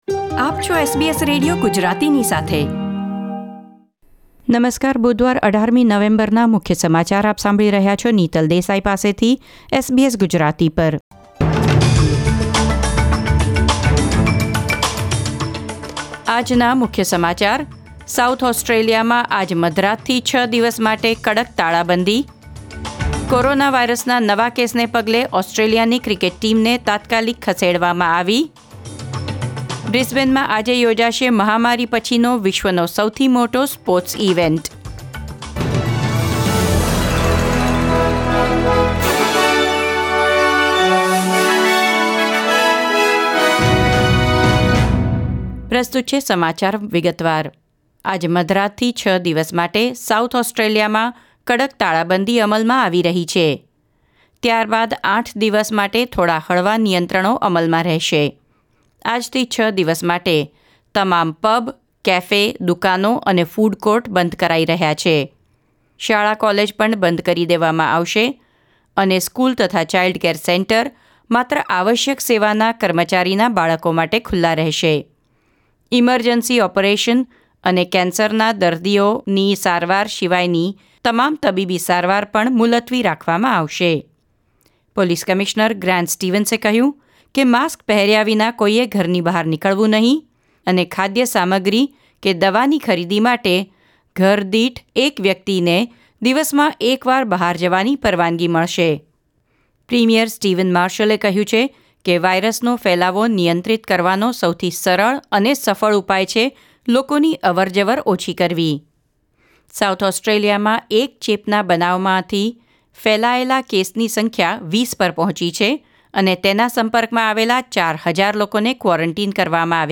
SBS Gujarati News Bulletin 18 November 2020 06:22 Long testing queues and panic buying have been reported in South Australia as Adelaide battles a new coronavirus cluster.